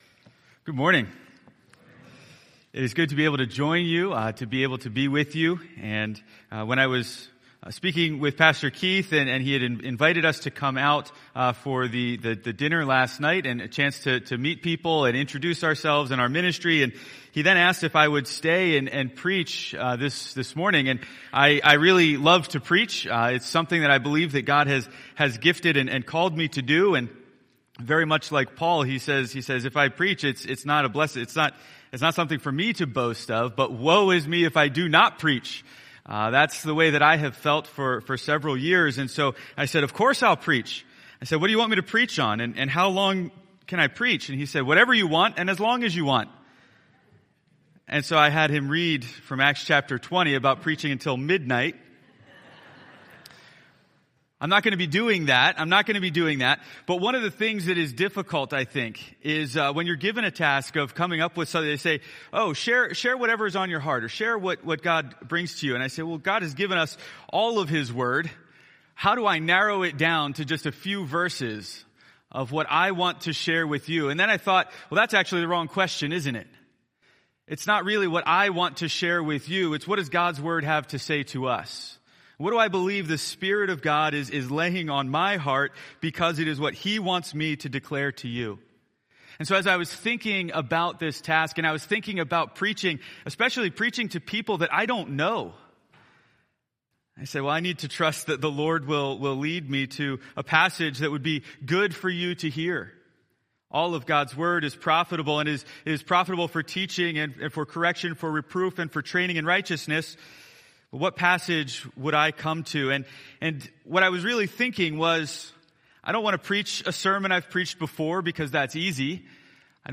A message from the series "2022 Sermons."